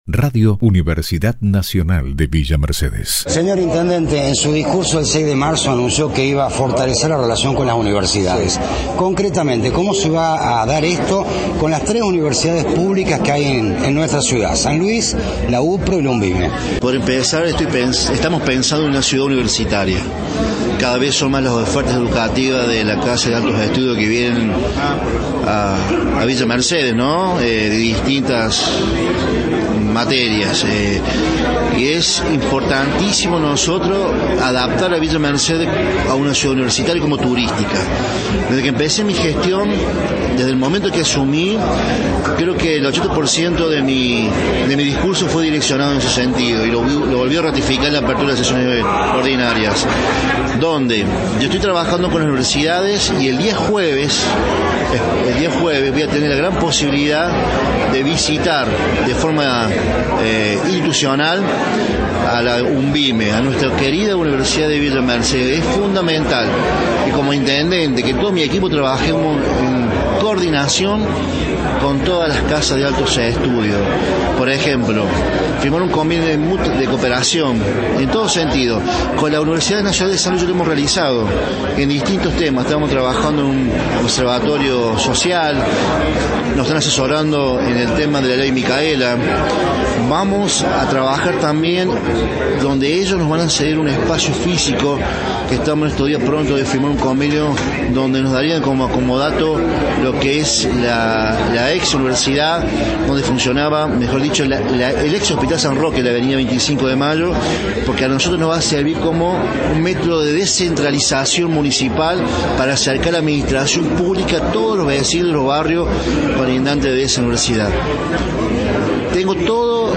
Al término de la misma concedió una entrevista exclusiva a Radio UNViMe 93.7, en la que ratificó su intención de reforzar la relación del Municipio con las Universidades que funcionan en Villa Mercedes, ratificó la firma de un convenio con la UNViMe y la implementación del Boleto Estudiantil Gratuito, entre otros temas.